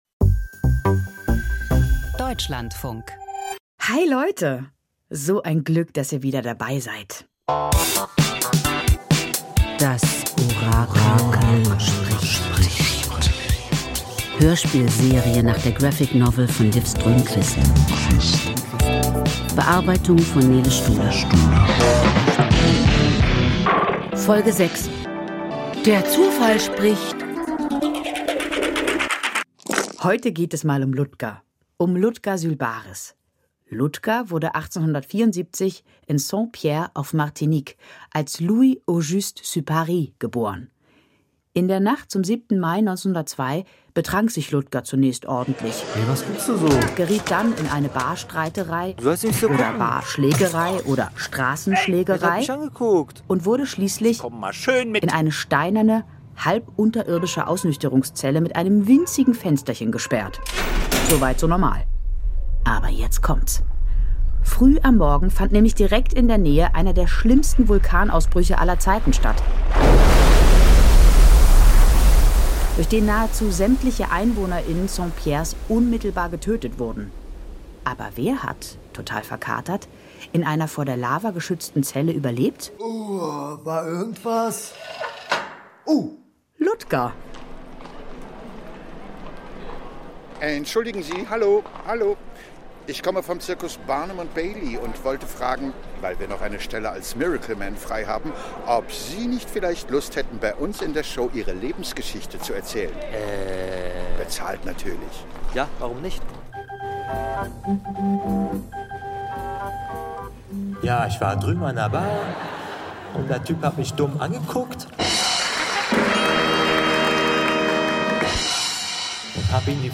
Das Orakel spricht – Hörspielserie nach Liv Strömquist
Serie Hörspiel Das Orakel spricht (6/7) Der Zufall spricht 21:46 Minuten Sind wir wirklich selbst so verantwortlich für unseren Erfolg oder Misserfolg, wie uns weisgemacht wird?